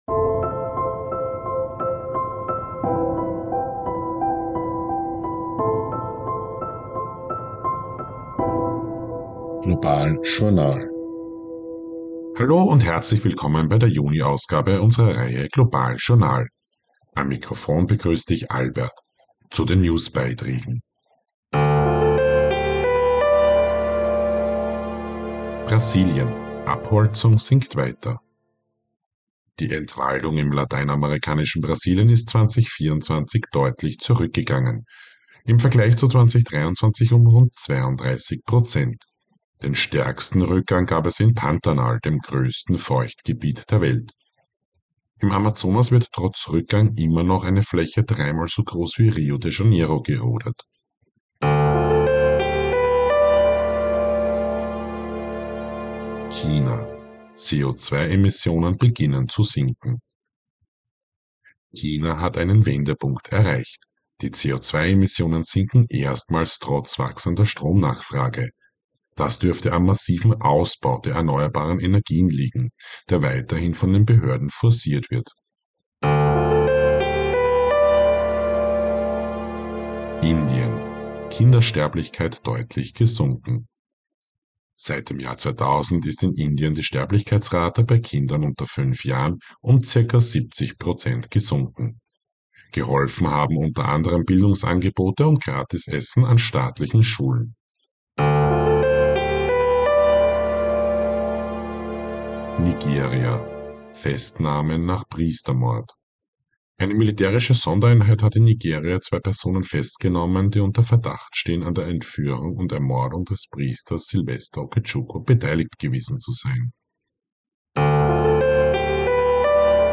News Update Juni 2025